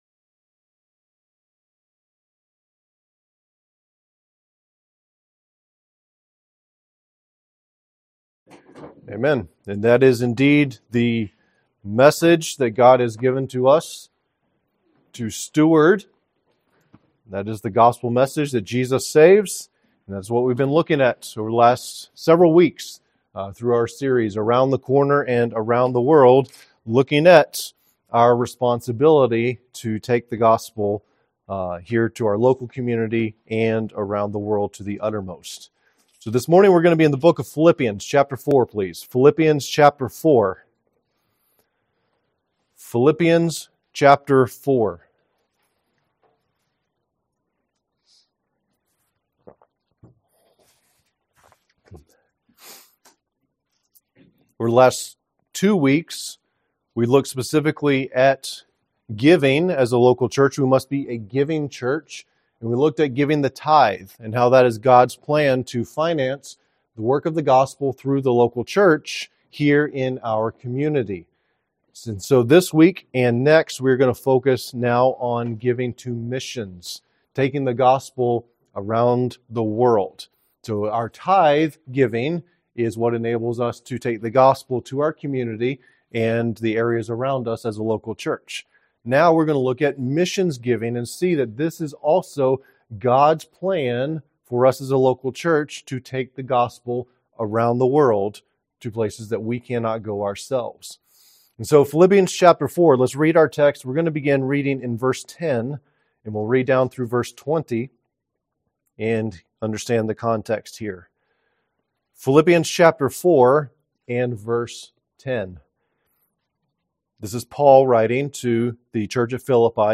Preached